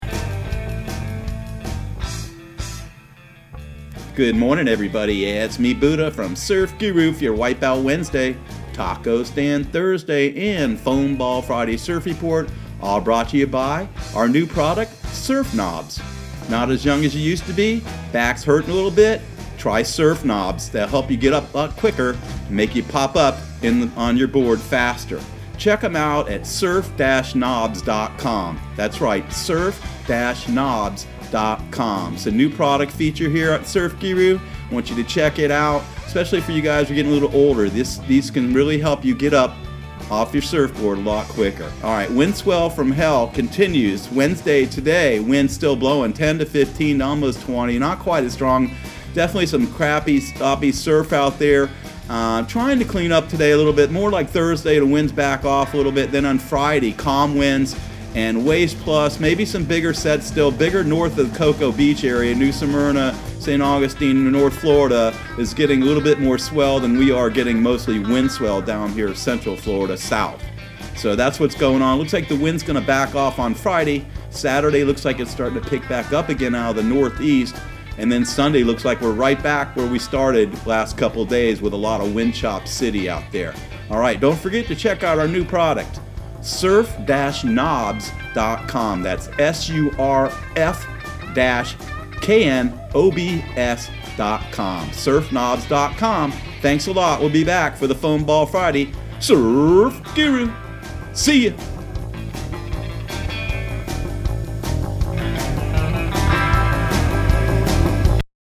Surf Guru Surf Report and Forecast 10/02/2019 Audio surf report and surf forecast on October 02 for Central Florida and the Southeast.